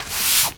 sweeping_broom_leaves_stones_13.wav